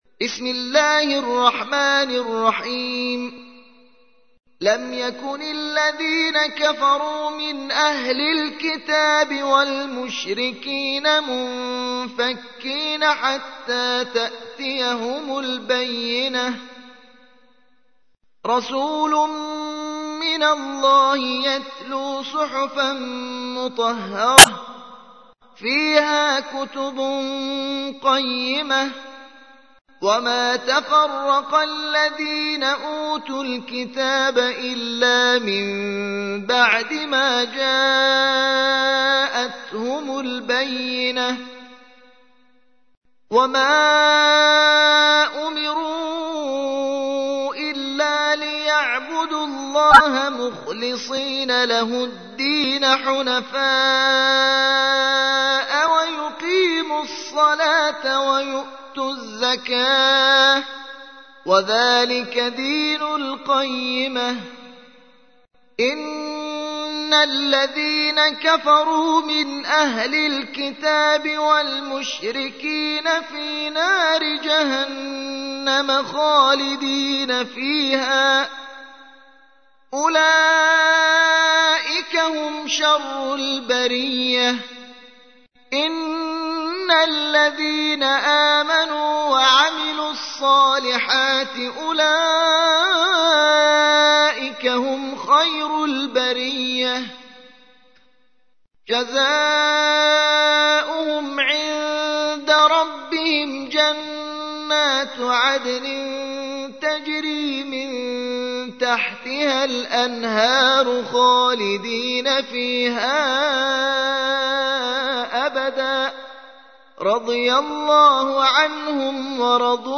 تحميل : 98. سورة البينة / القارئ محمد حسين سعيديان / القرآن الكريم / موقع يا حسين